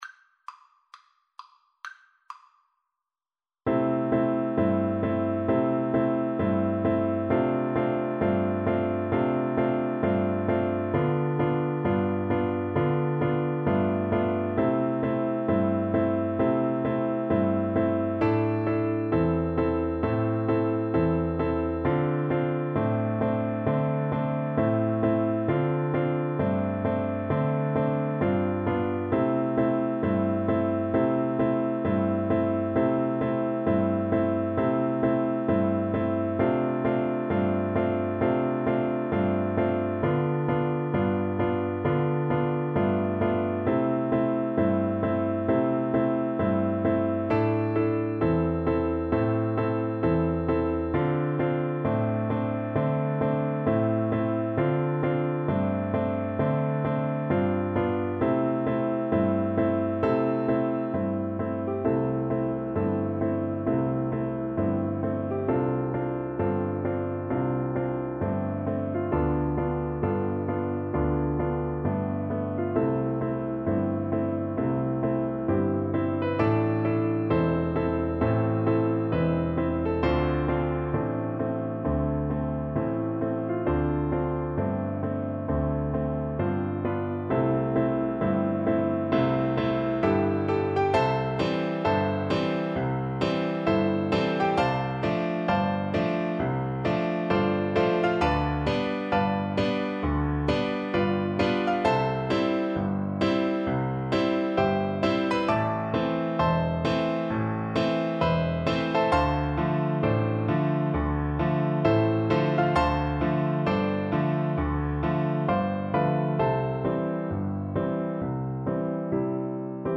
Soprano (Descant) Recorder version
2/2 (View more 2/2 Music)
Blues Tempo (=66)
Jazz (View more Jazz Recorder Music)